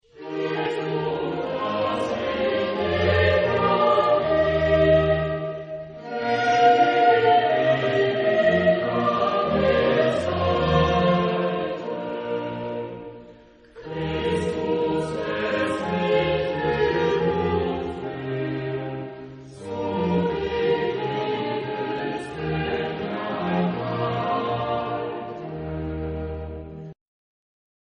Epoque: 18th century
Genre-Style-Form: Sacred
Type of Choir: SATB  (4 mixed voices )